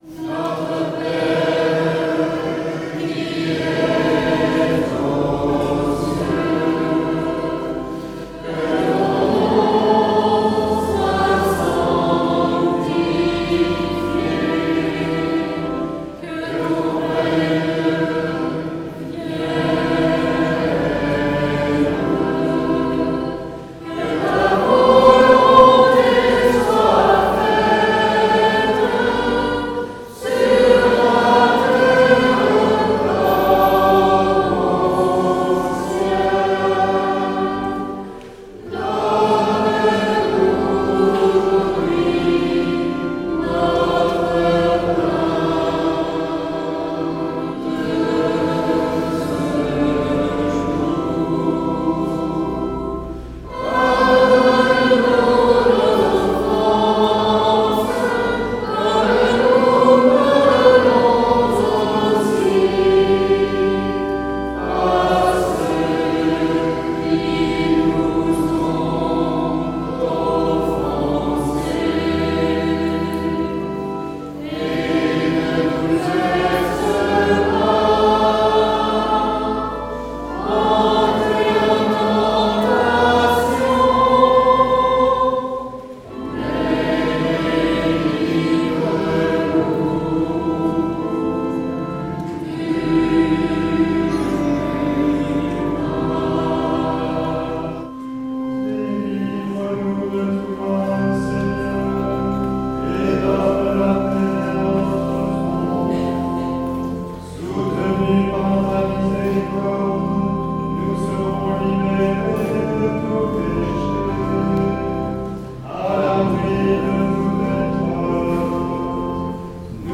♫ Enregistrement du dimanche 22 mai 2022 en l'église Notre-Dame de l'Assomption - Embolisme psalmodié à 2 voix